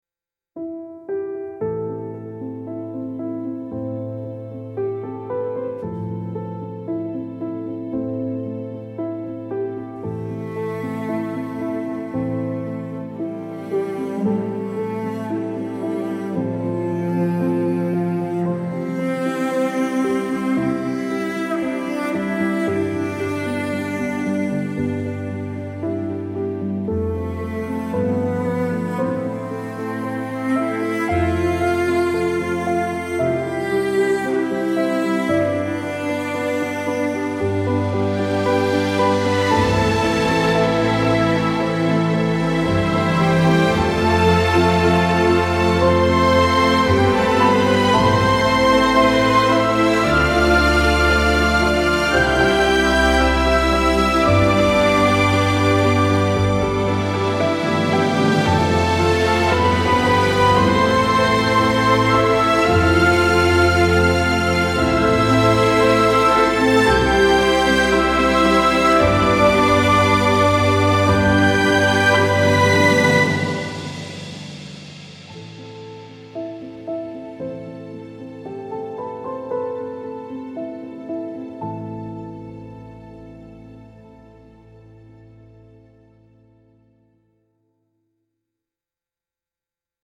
lush romantic orchestral swell with soaring melody and warm cellos